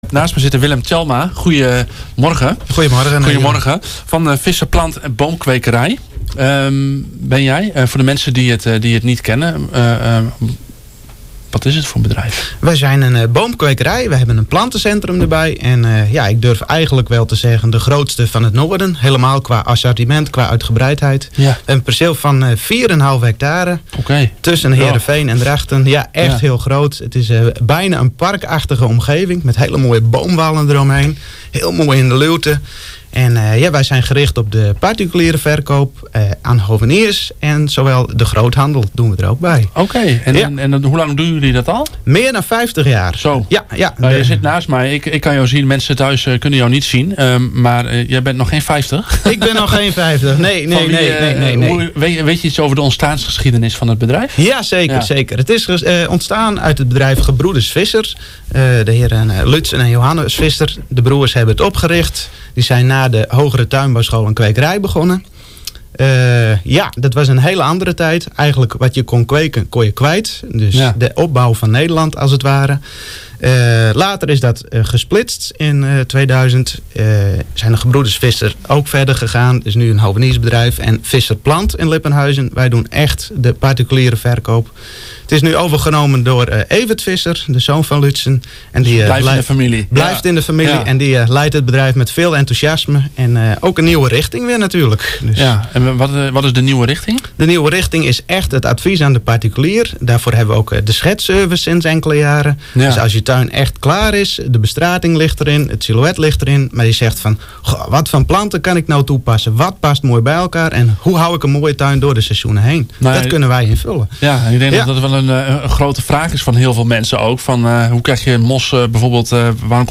op de radio bij Smelme FM